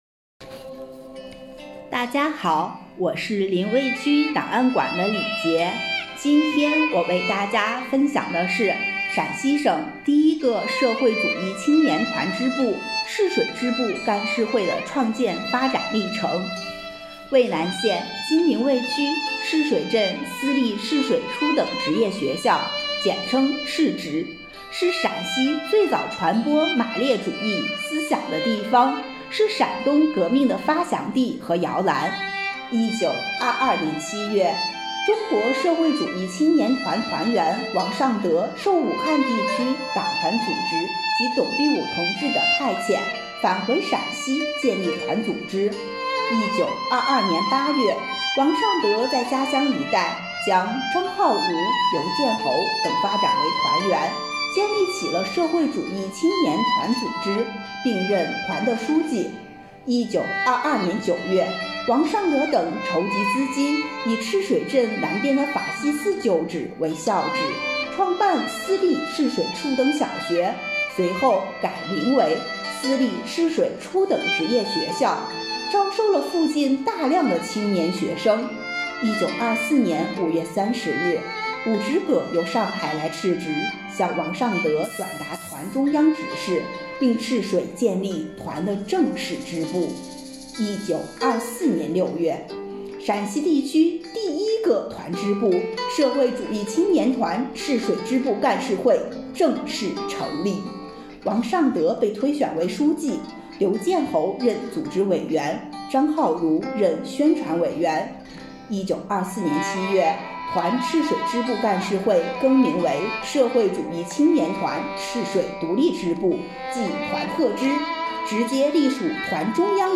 【红色档案诵读展播】陕西省第一个社会主义青年团支部——赤水支部干事会